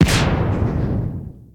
.开火6.ogg